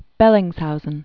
(bĕlĭngz-houzən), Fabian Gottlieb von 1778-1852.